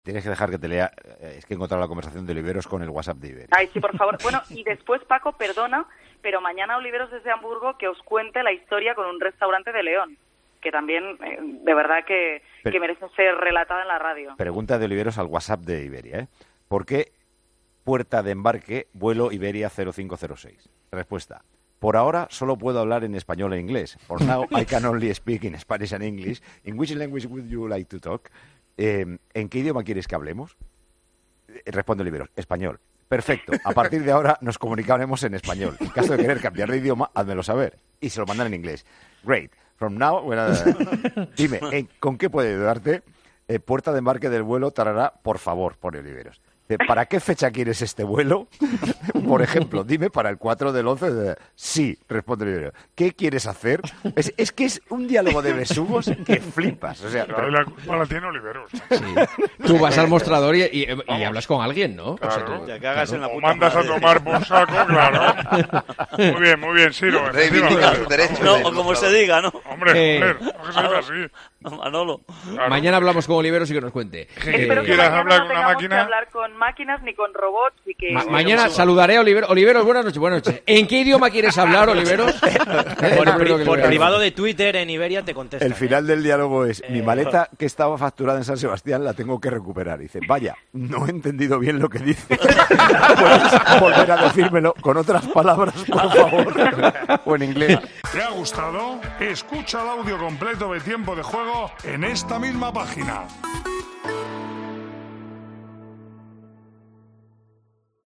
Conversación que desató las carcajadas de todo el equipo de comentaristas.